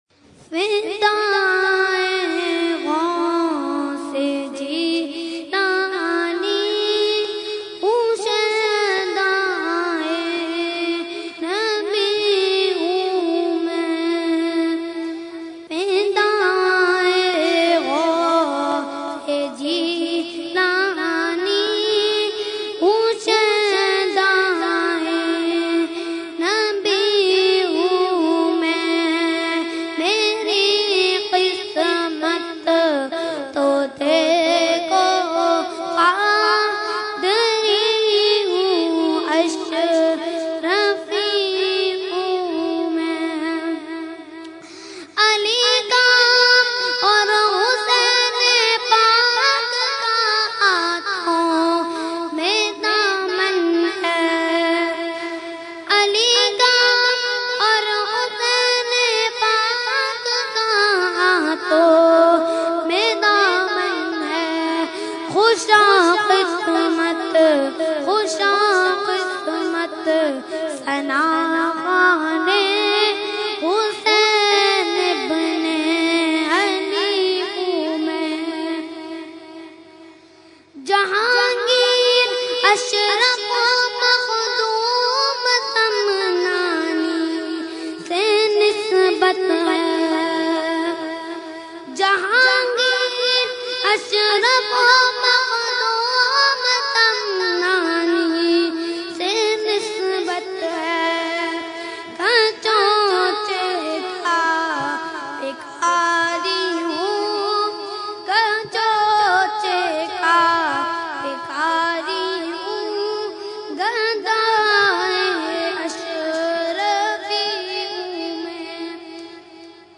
Category : Manqabat | Language : UrduEvent : Urs e Makhdoom e Samnani 2015